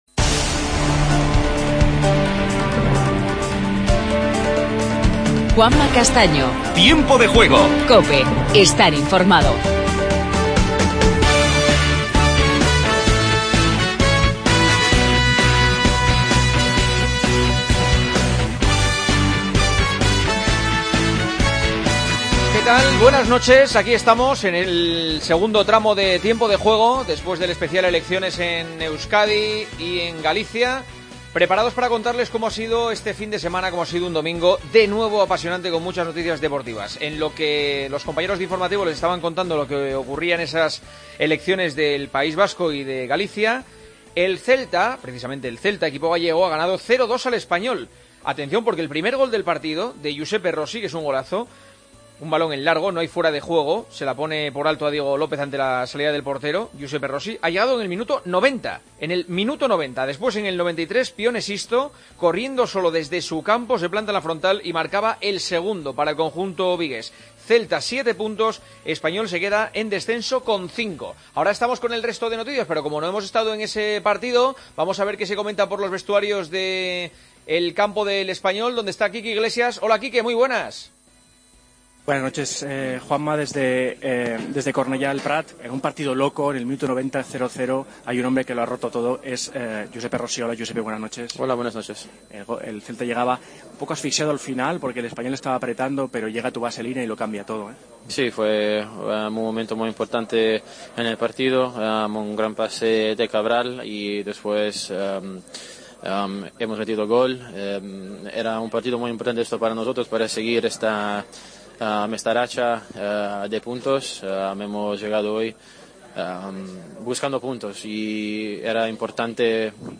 Entrevista a Rossi, delantero del Celta.
Entrevista a Marc Márquez, campeón del GP de Aragón de Moto GP.